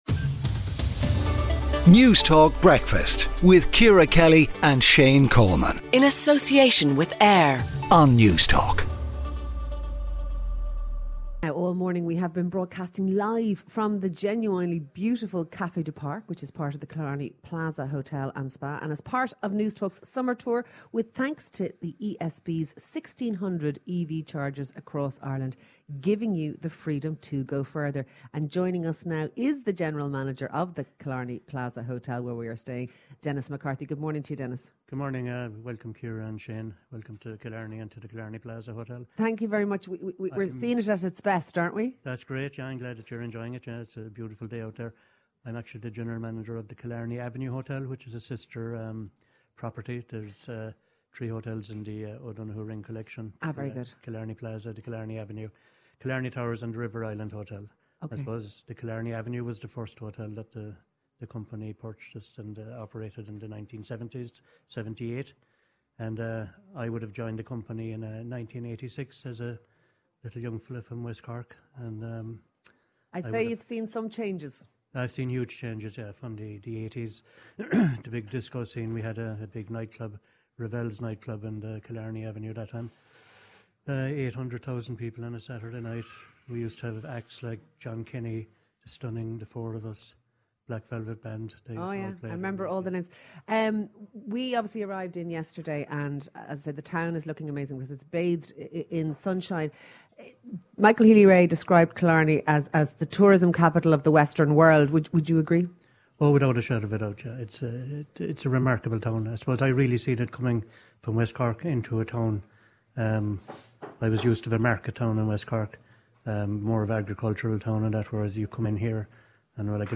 All morning, we’ve been broadcasting live from the beautiful Café Du Parc part of the Killarney Plaza Hotel and Spa As part of Newstalk Summer Tour with thanks to ESB’s 1600 EV chargers across Ireland – giving you the freedom to go further.